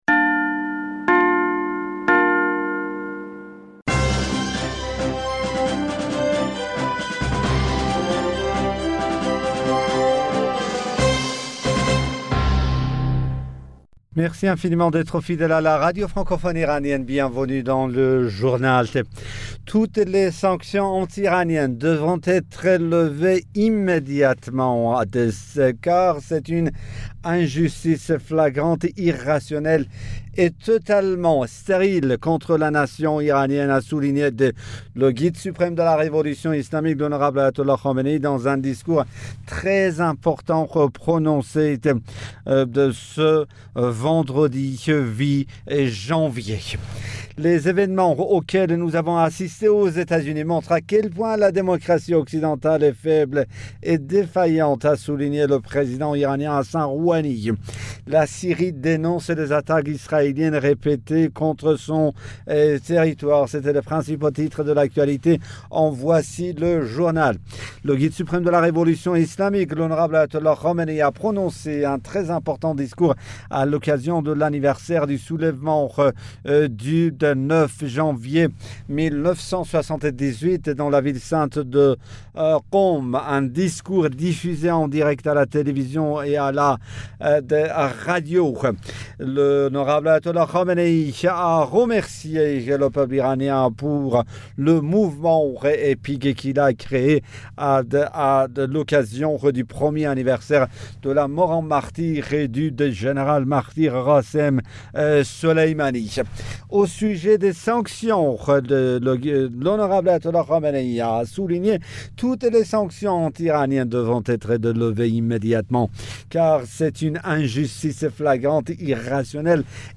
Bulletin d'informationd u 08 Janvier 2021